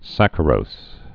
(săkə-rōs)